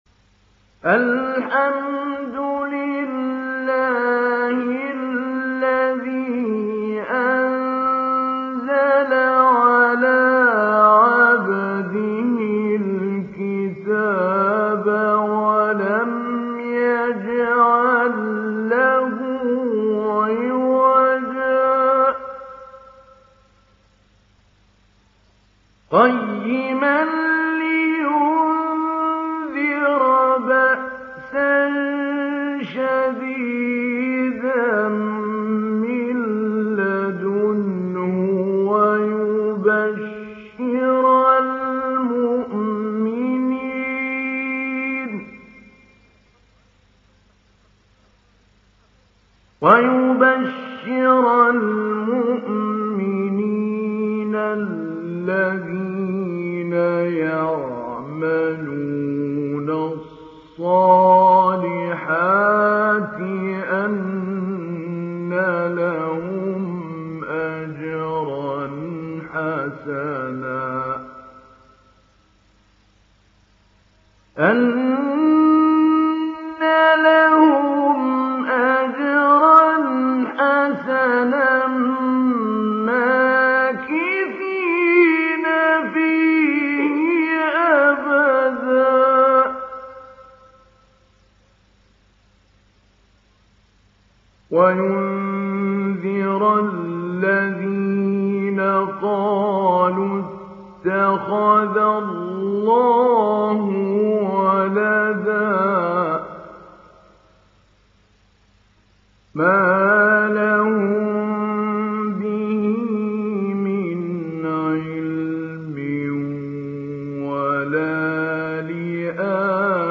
دانلود سوره الكهف mp3 محمود علي البنا مجود روایت حفص از عاصم, قرآن را دانلود کنید و گوش کن mp3 ، لینک مستقیم کامل
دانلود سوره الكهف محمود علي البنا مجود